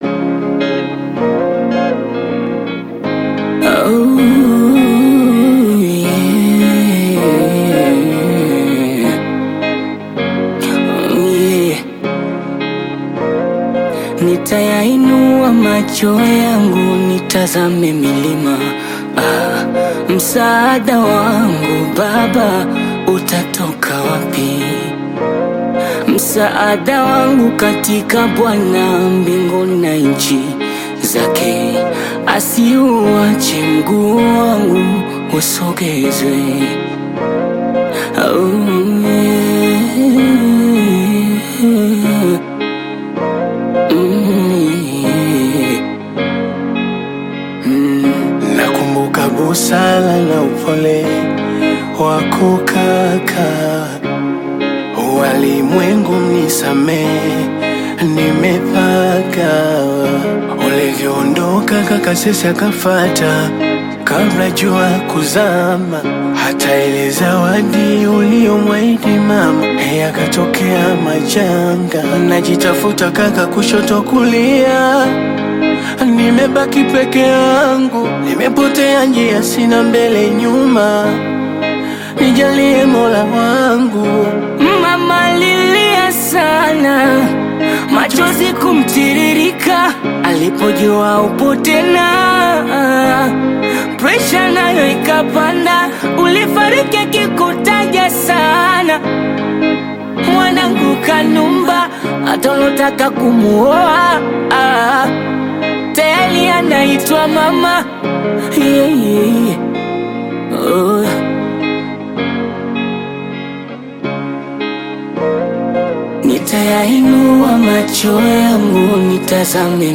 Bongo Flava
voice is so smooth and soulful
African Music